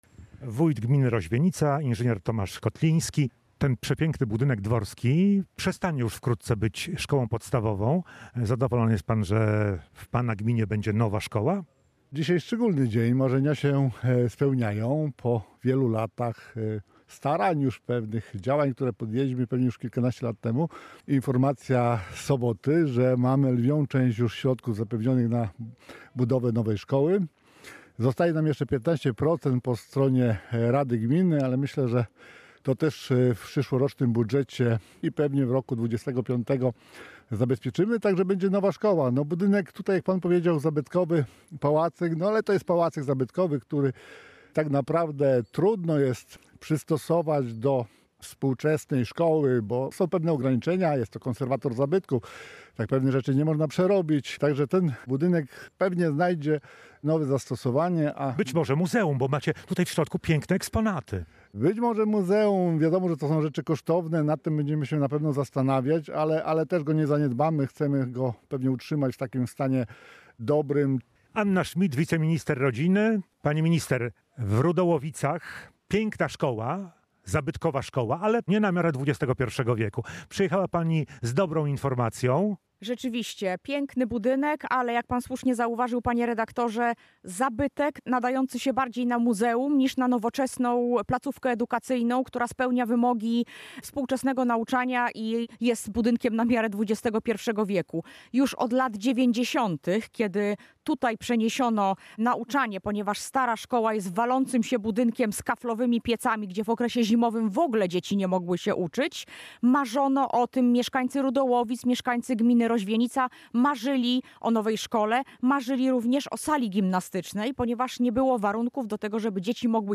– mówiła na spotkaniu w Rudołowicach Anna Schmidt, wiceminister rodziny.
Relacja